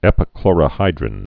(ĕpĭ-klôrə-hīdrĭn)